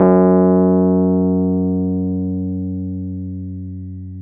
RHODES-F#1.wav